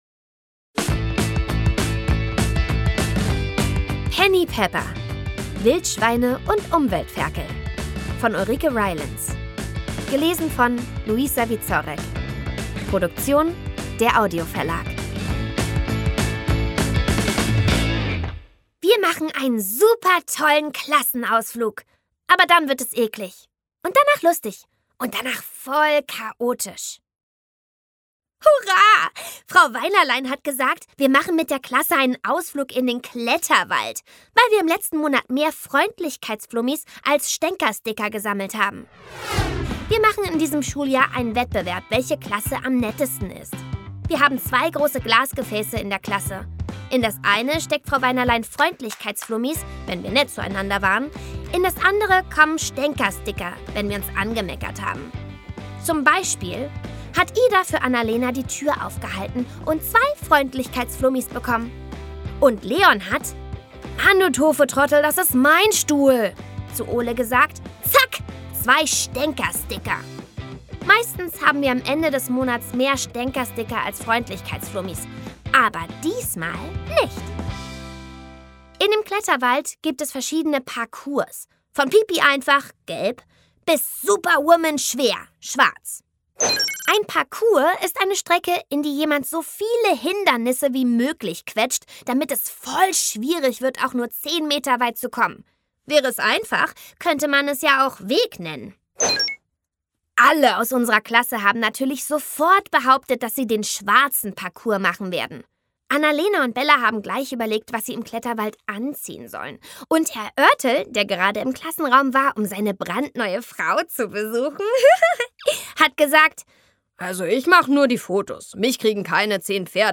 Szenische Lesung mit Musik